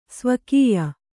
♪ svakīya